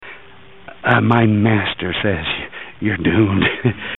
Category: Radio   Right: Personal
Tags: Radio The Curse Of Dracula Play Horror Bram Stoker